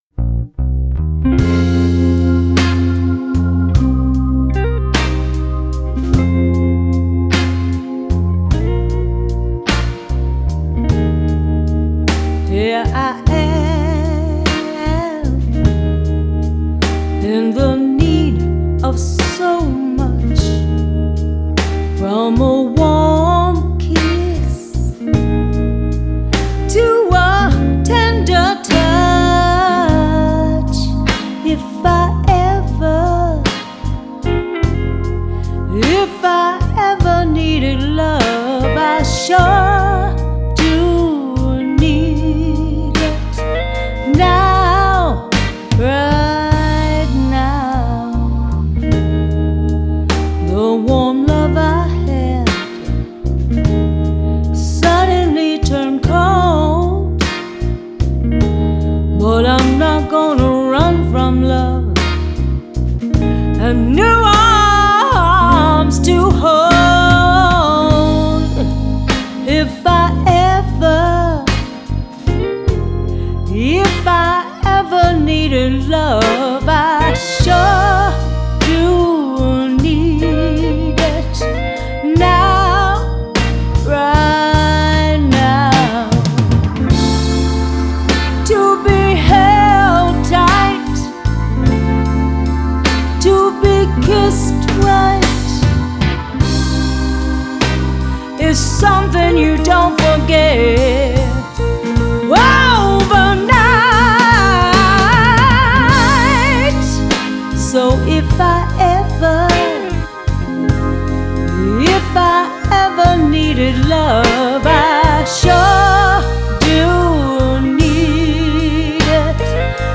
Genre: Blues